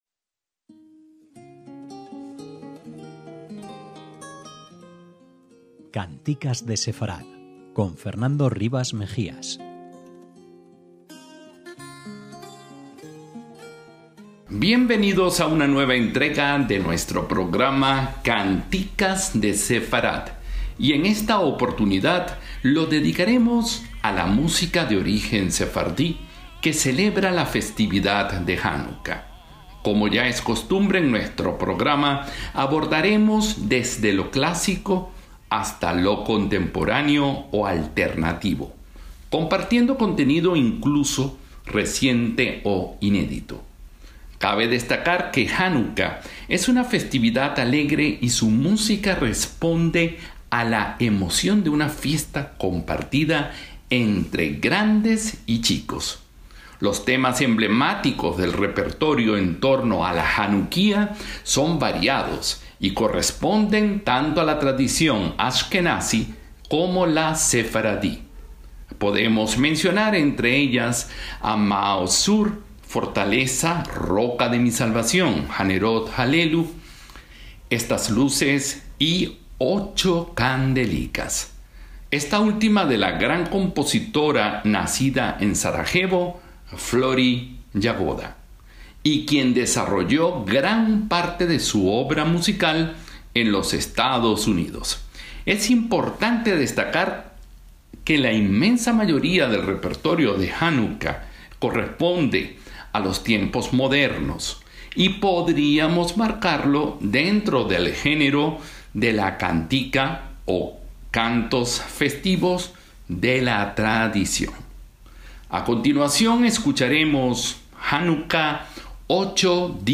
Un arreglo que asoma el origen balcanico de la autora con acompañamiento de guitarras, pandereta y acordeón.
Los arreglos musicales de este tema se caracterizan por la presencia de metales los cuales dan al mismo un aire marcadamente balcanico.